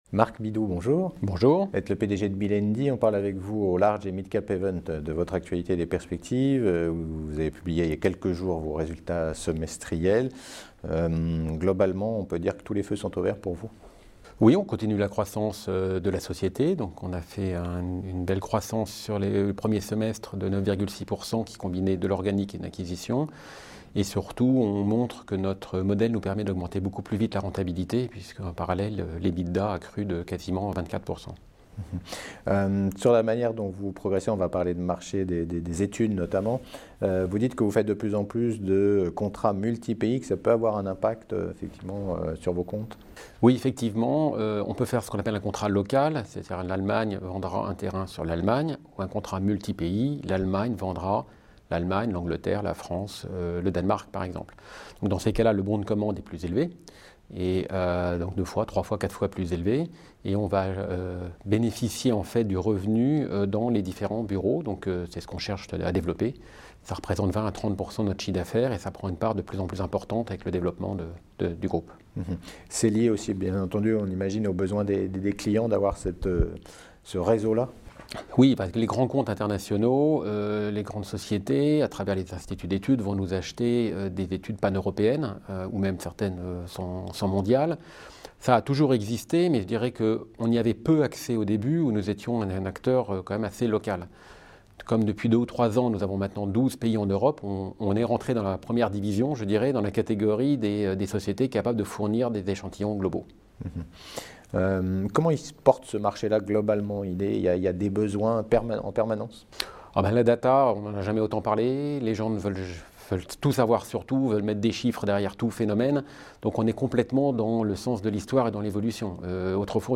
La Web Tv rencontre les dirigeants au Large et Midcap Event 2019.